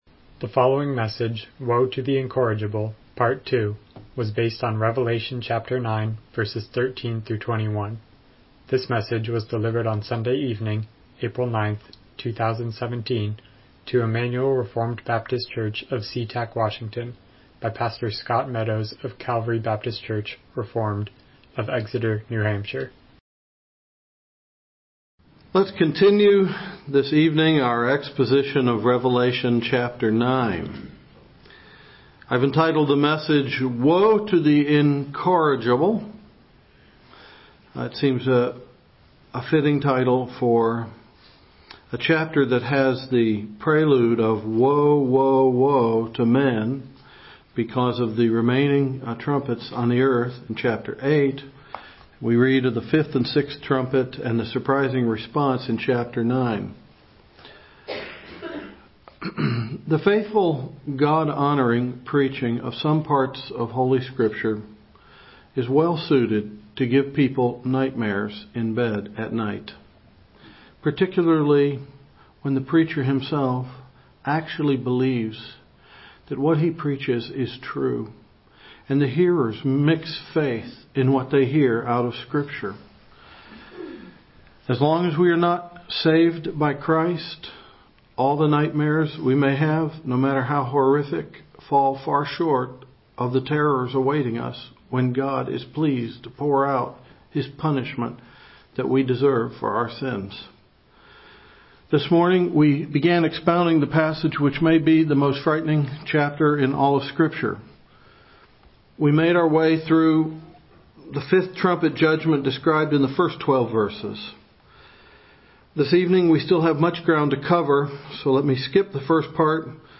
Miscellaneous Passage: Revelation 9:13-21 Service Type: Evening Worship « Woe to the Incorrigible